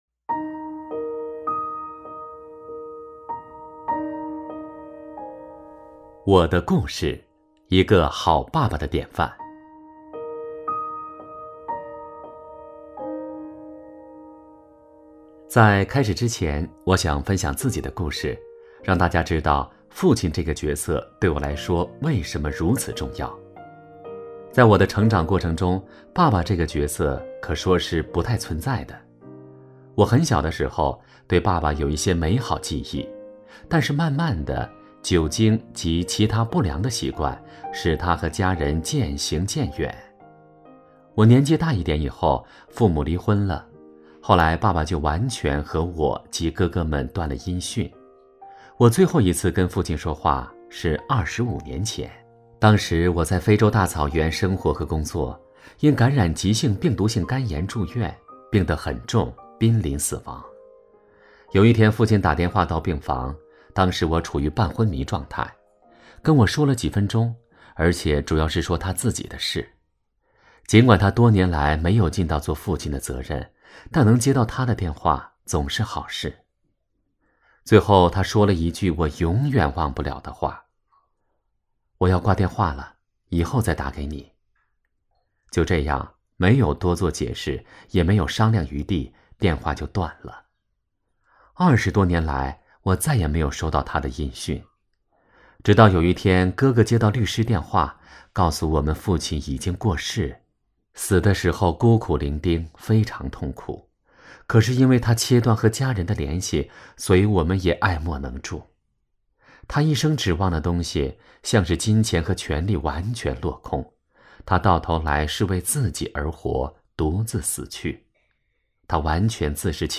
首页 > 有声书 > 婚姻家庭 | 成就好爸爸 | 有声书 > 成就好爸爸：03 我的故事